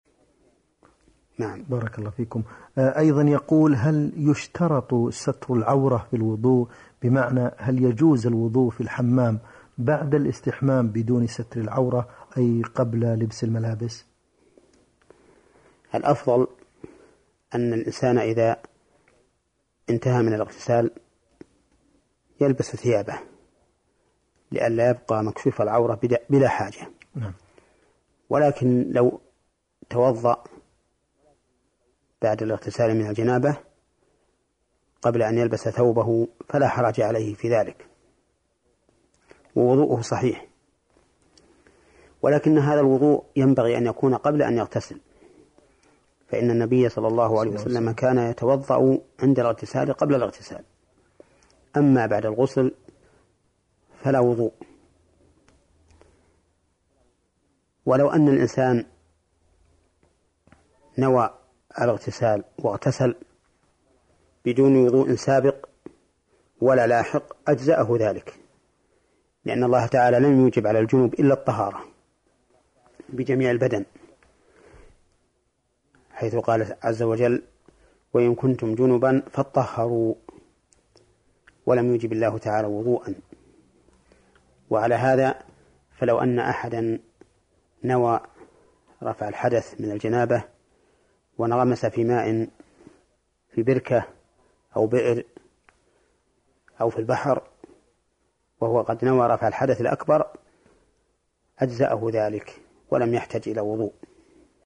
*👈🏻فَـضيلَـة الــشّيــخِ الـعـَـلّامَـة/*